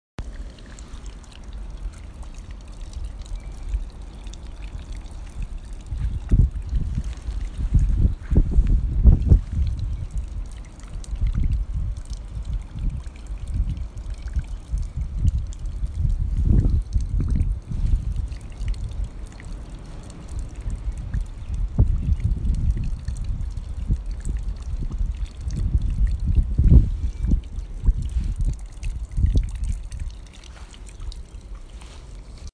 RSR Booth 2/8/18 C2 Far East Booth 3PM
Field Recording
beeping , bottle opening
door